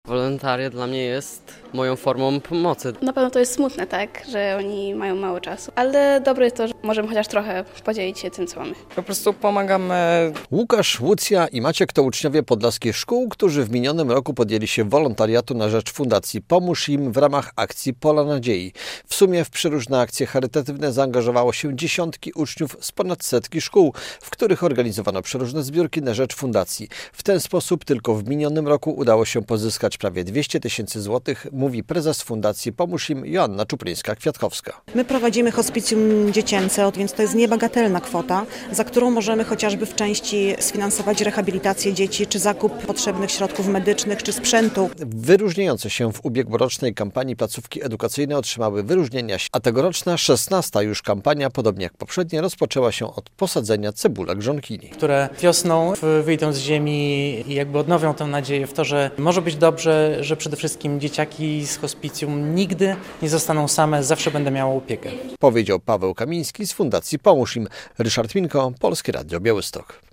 Były wyróżnienia dla najaktywniejszych wolontariuszy i wspólne sadzenie cebulek żonkili. Prowadząca hospicjum dla dzieci Fundacja "Pomóż Im" zainaugurowała w Białymstoku XVI edycję akcji Pola Nadziei.
Rozpoczęła się kolejna edycja Pól Nadziei w Białymstoku - relacja